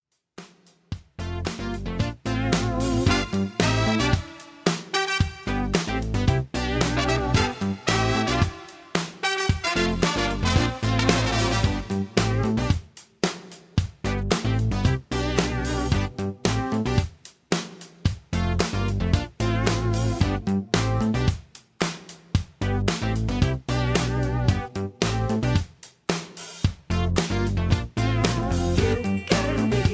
(Key-G)